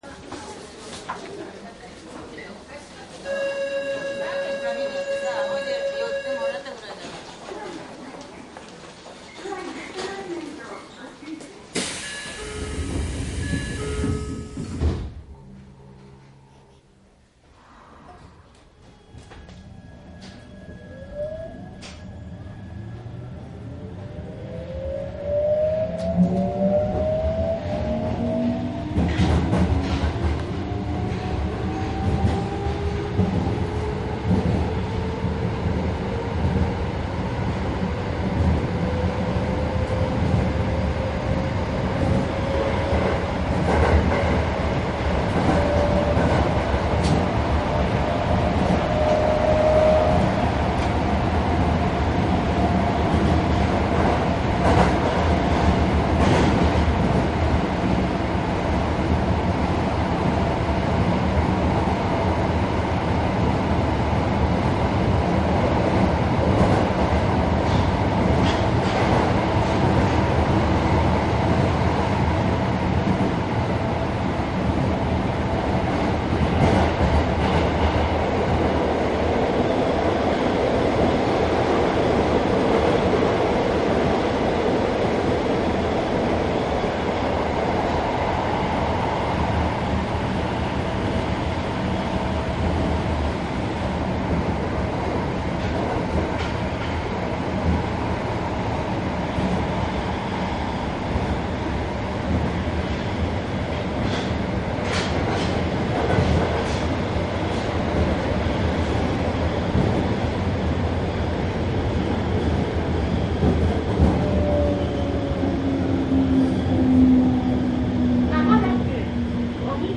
営団丸ノ内線02系  回生ブレーキ試験車  走行音♪
当時の営団地下鉄で02系39Fを使って何かの試験（おそらく電気ブレーキ？）の試験を行なった車輌です。停止寸前まで音がしますので地下で遭遇すると音ですぐ分かった編成です。しばらく試験的な音がしてましたが現在はありふれた三菱の音に戻っています。
■池袋→荻窪 02－239
マスター音源はデジタル44.1kHz16ビット（マイクＥＣＭ959）で、これを編集ソフトでＣＤに焼いたものです。